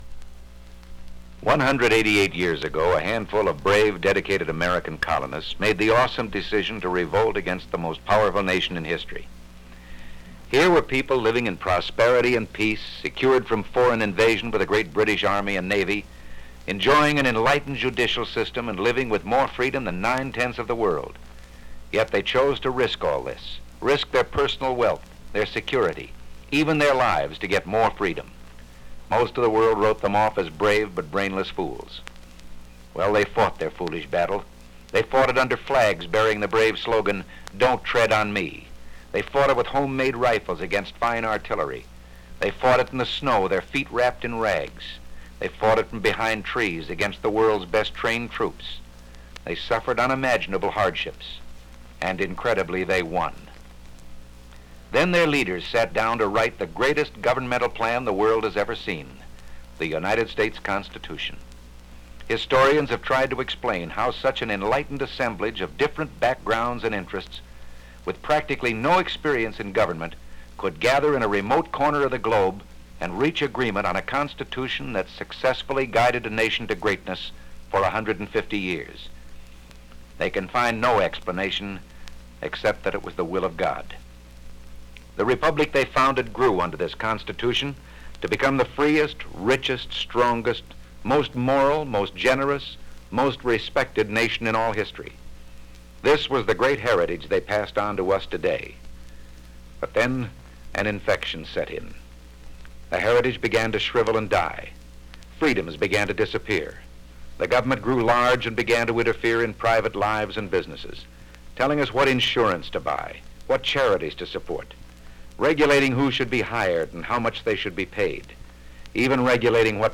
Ronald Reagan narration “A Choice for Americans” for Goldwater campaign
Disc Audio Format (LP 33 1/3), 1964.